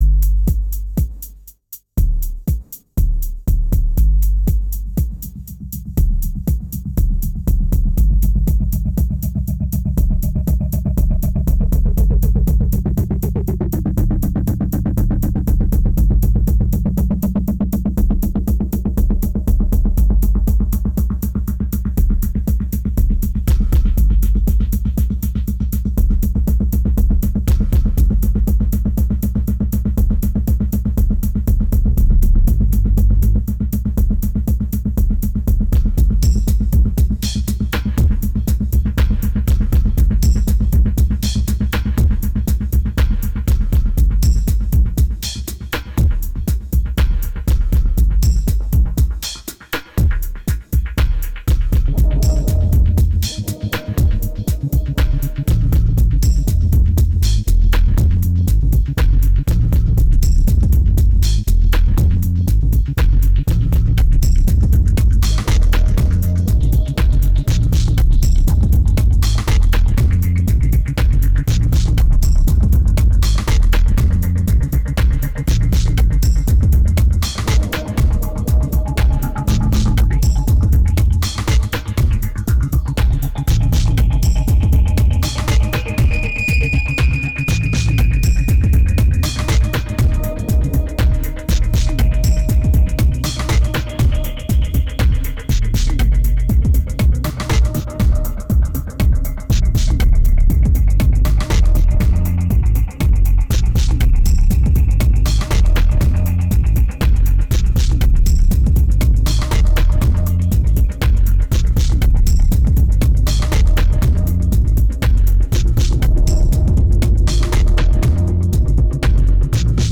It's raw, it's dirty and it's lame, but are you up to it ?